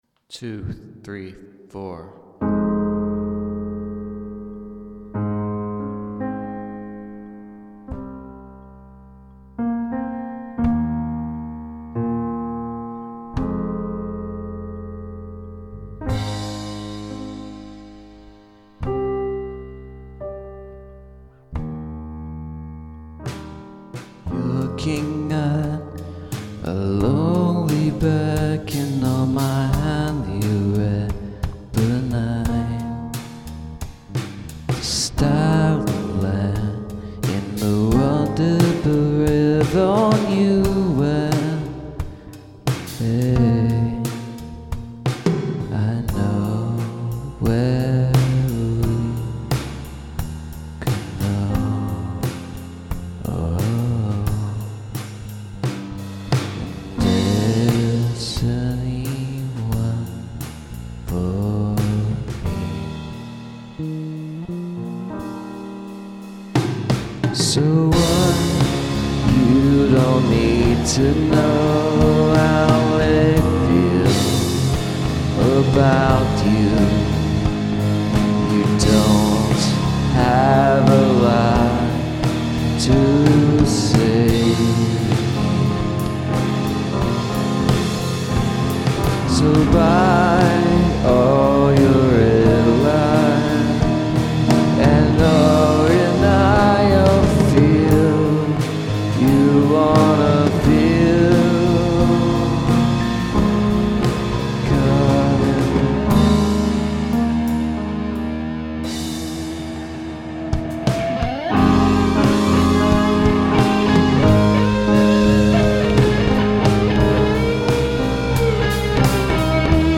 The instrumentation is pretty similar to last week... piano, drums, vocals... this time some guitar, which I struggled to fit in there without overwhelming the rest (except for when it needed to overwhelm things). I did some sort of bizarre heavy metal bass drum thing at the end, which I tried to sync up with on the bass and guitar. Thankfully, I recorded this piano demo with vocal to a click this time.
I did manage to get feedback going with a software simulated amp, which basically required me to turn up the monitoring while I was recording the guitar.
As usual, improvised vocals, so don't try to listen to closely.
This one has some nice volume.